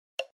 beep_time.mp3